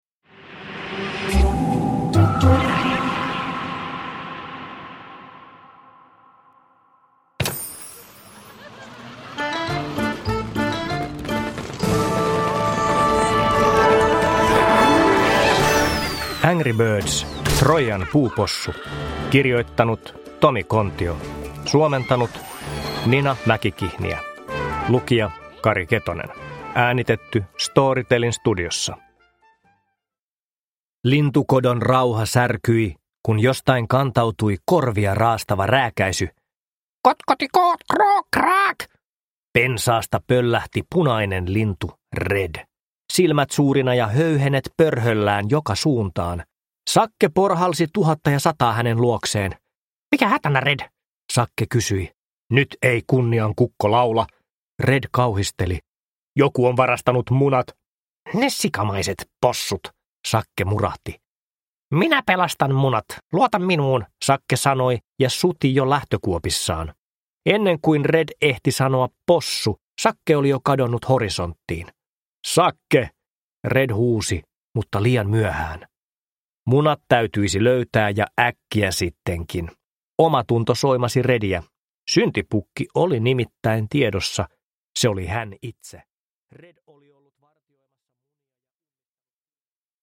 Angry Birds: Troijan puupossu – Ljudbok – Laddas ner